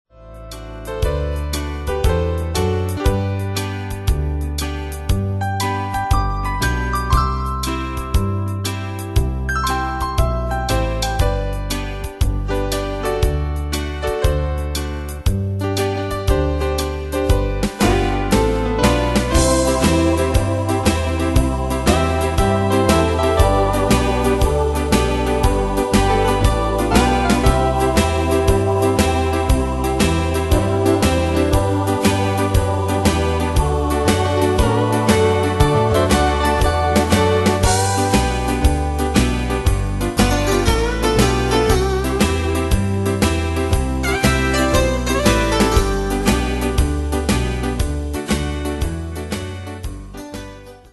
Style: Country Ane/Year: 1987 Tempo: 119 Durée/Time: 3.23
Danse/Dance: TwoSteps Cat Id.
Pro Backing Tracks